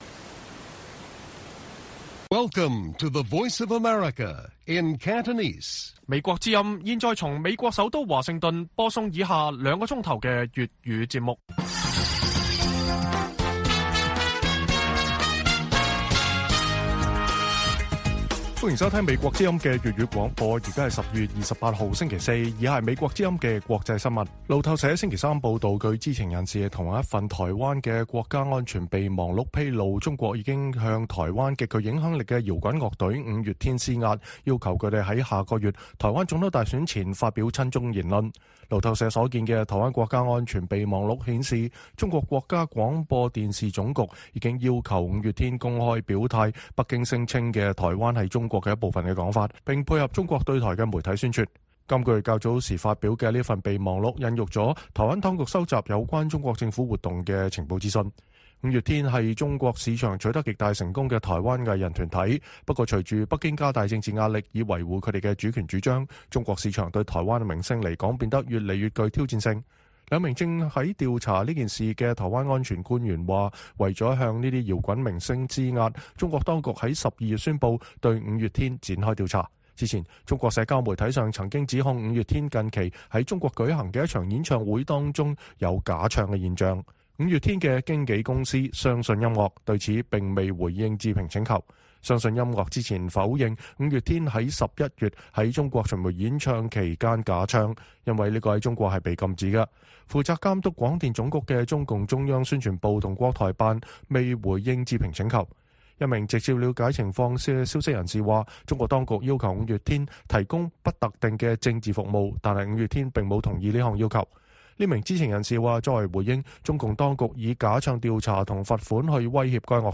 粵語新聞 晚上9-10點: 專家指香港多項國際排名大幅下跌或影響聯通國際角色